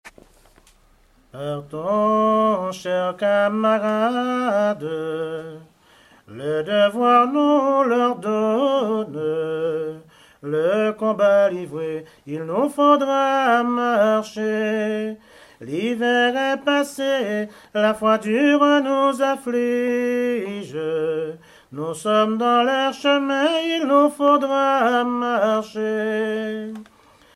chanteur(s), chant, chanson, chansonnette
Genre strophique
chansons anciennes recueillies en Guadeloupe
Pièce musicale inédite